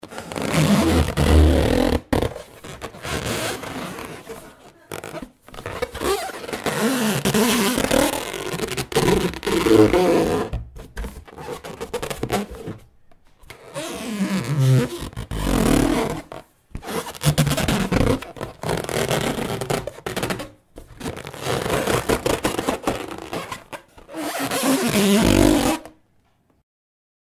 Звуки шариков
Скрип шарика